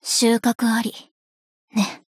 貢獻 ） 协议：Copyright，其他分类： 分类:爱慕织姬语音 無法覆蓋此檔案。